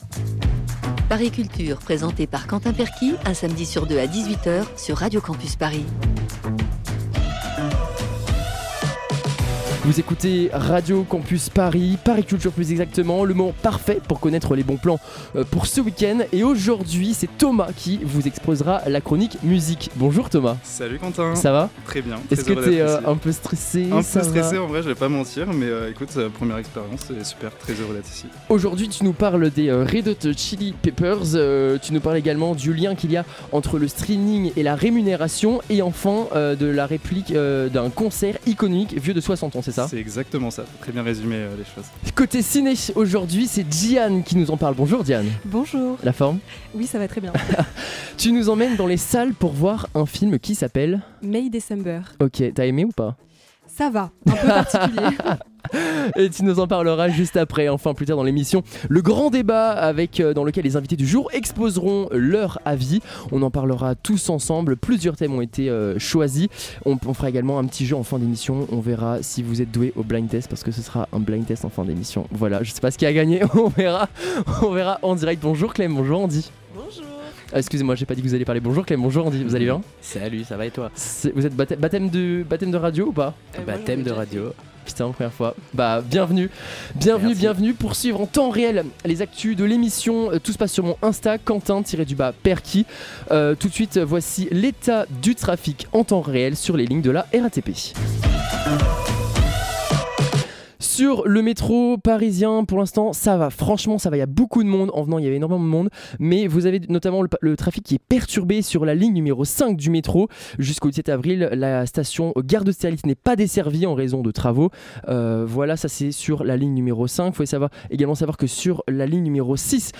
Type Magazine Culture
Le Grand Débat : les invités du jour nous exposent leur point de vue concernant différents sujets (les Jeux Olympiques, l’IA…). Le Jeu : blind test musical … qui va remporter cette épreuve ?